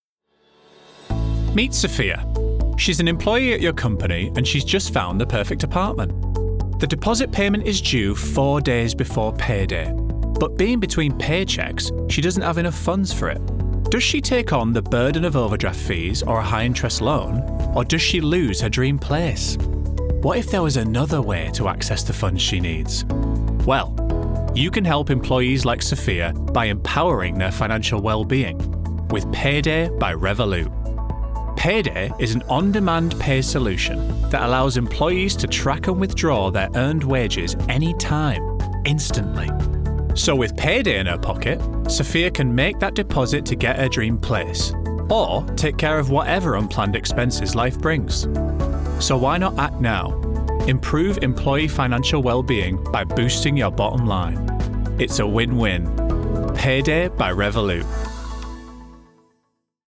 Corporate Showreel
Male
Yorkshire